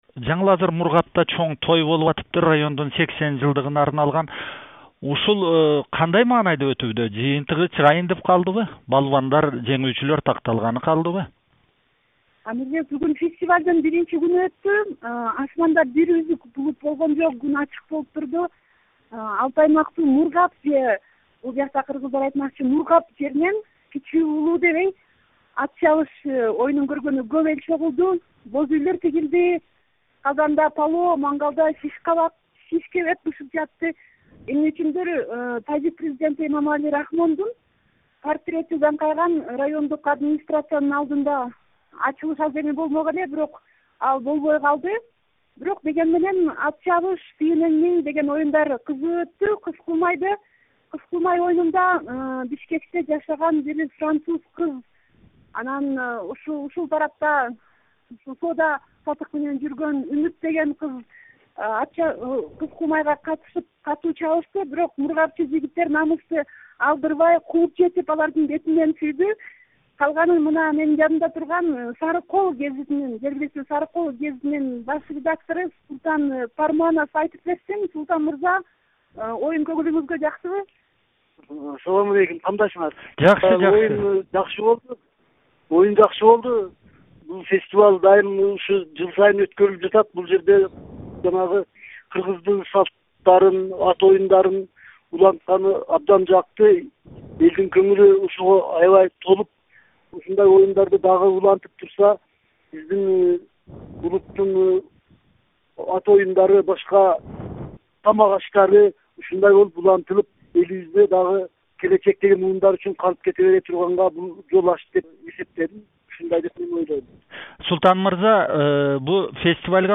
репортажы